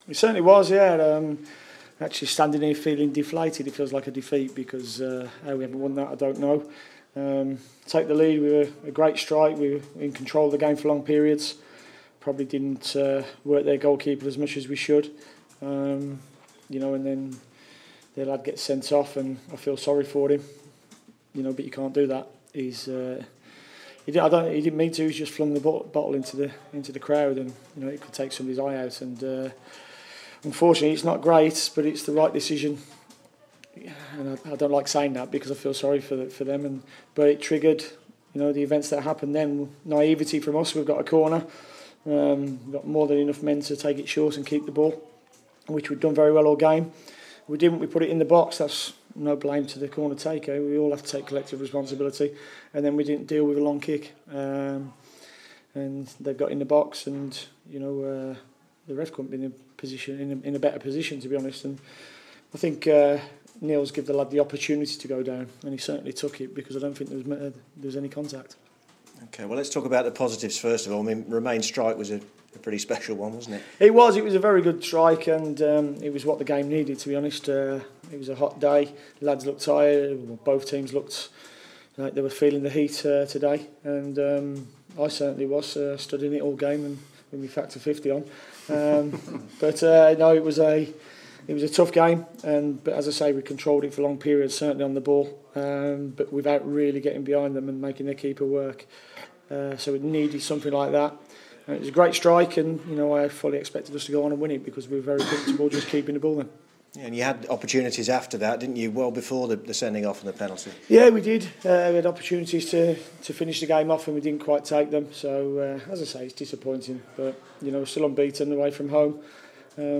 Walsall manager Dean Smith reacts to the Peterborough draw: "It feels like a defeat"